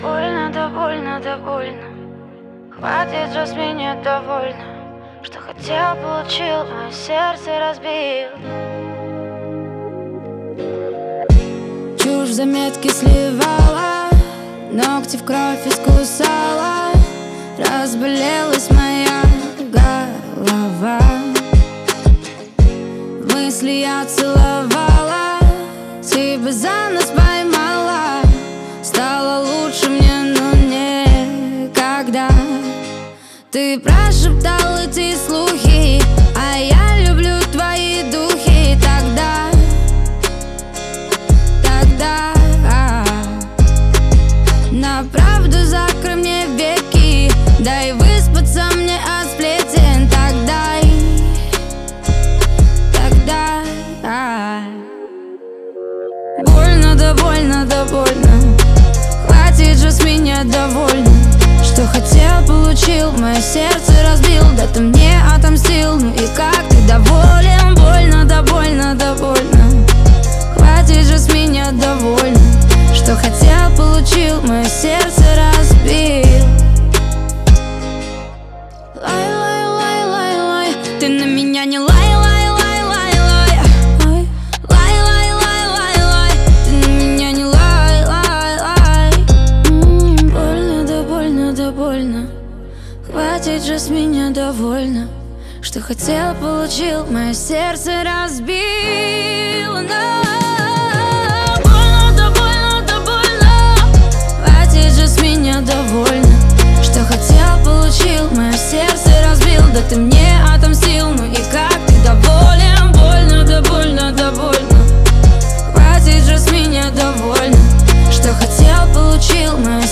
ее голос наполнен искренностью и страстью.